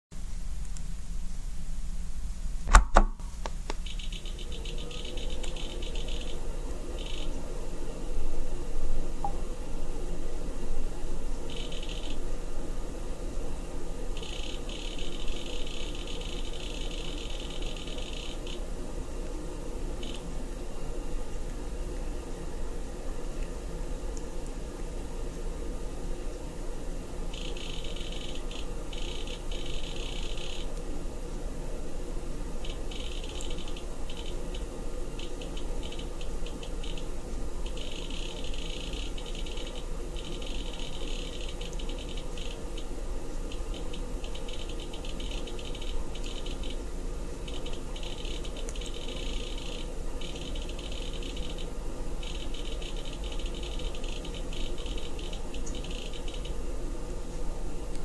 nextstation_hdd_clicker.mp3